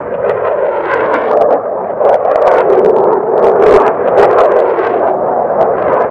rocket_fly.ogg